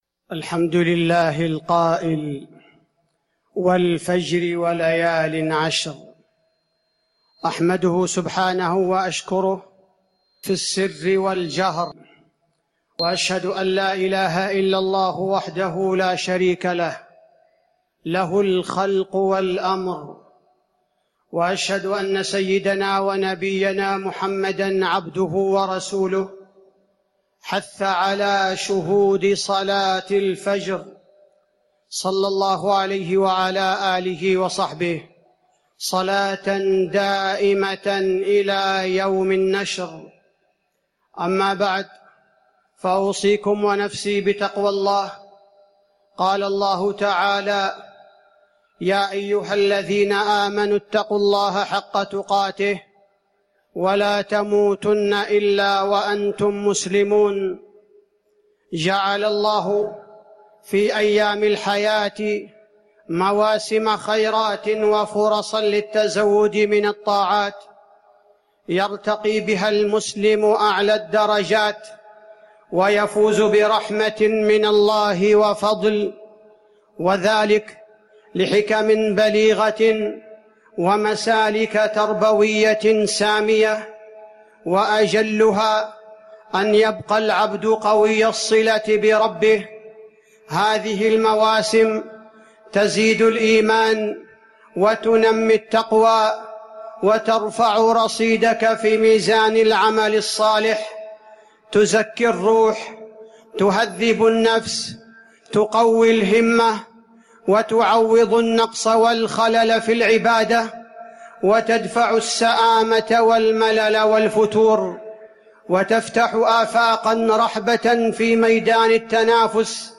تاريخ النشر ١ ذو الحجة ١٤٤٠ هـ المكان: المسجد النبوي الشيخ: فضيلة الشيخ عبدالباري الثبيتي فضيلة الشيخ عبدالباري الثبيتي فضل عشر ذي الحجة